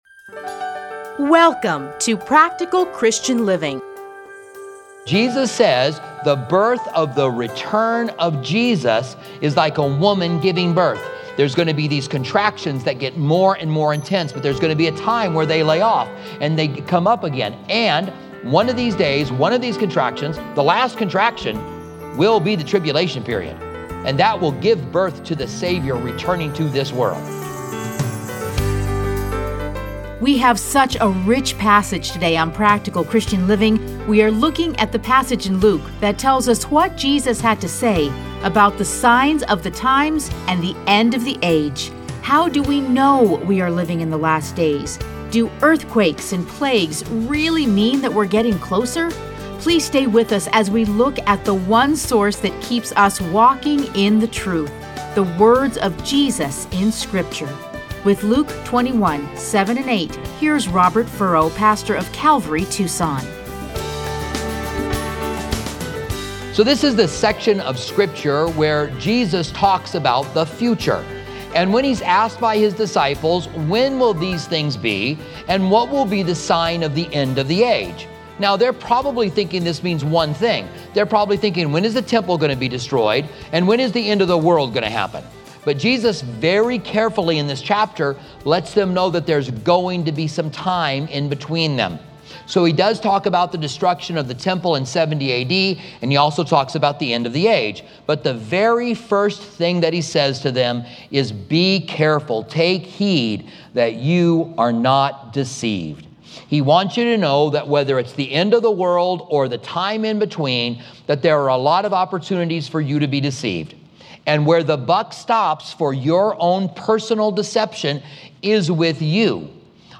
Listen to a teaching from Luke 21:7-8.